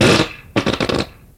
描述：dloaded from freesound with CC0 , Sliced, Resampled to 44khZ , 16bit , mono , without chunk info in file.Ready for use 47 farts in 1 folder ;)
标签： comedy fart effect farts sfx soundfx sound